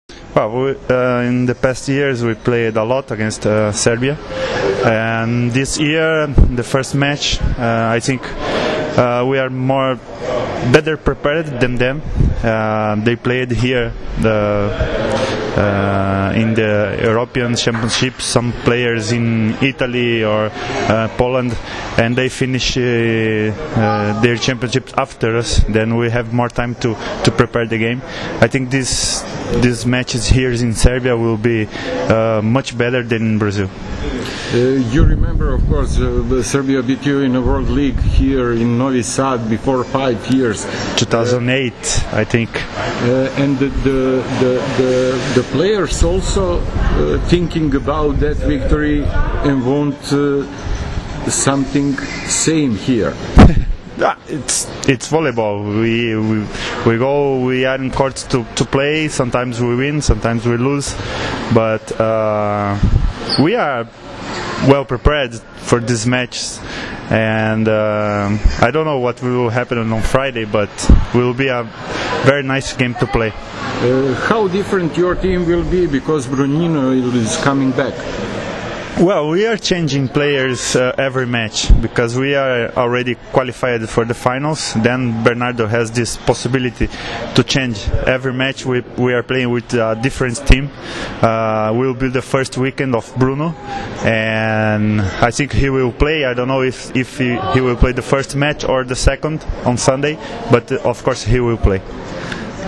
U razgovoru za sajt OSS, Murilo Endreš, primač i jedan od najboljih i najiskusnijih reprezentativaca “karioka” naglasio je da su mečevi sa Srbijom uvek posebni i da očekuje odlične utakmice u petak i nedelju.
IZJAVA MURILA ENDREŠA